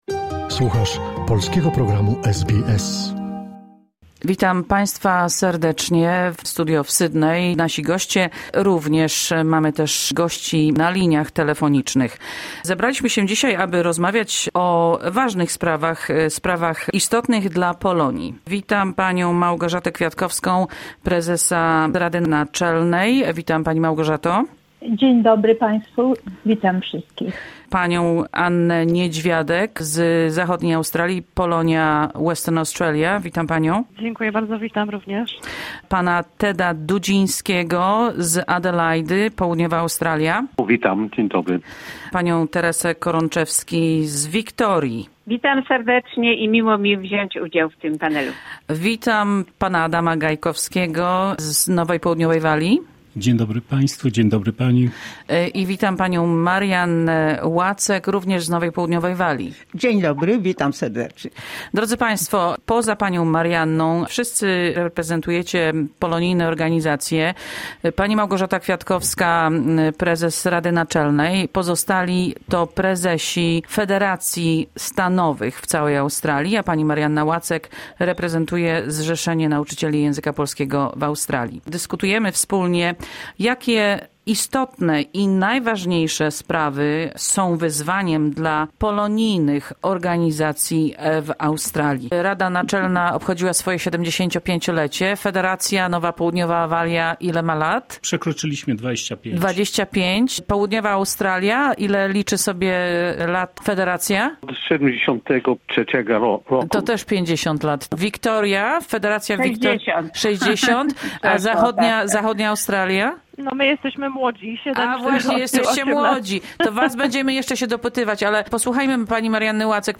Debata: Polonijne organizacje w Australii - jak przekazywać młodym pokoleniom?